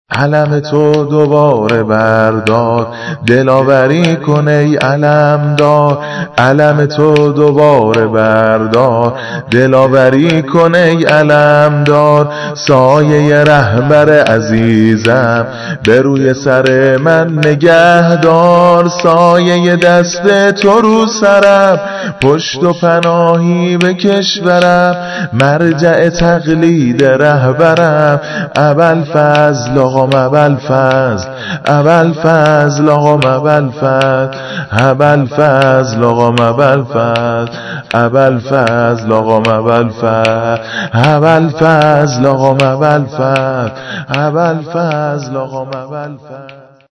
قمر بنی هاشم - - -- - -سرود ميلاد- - -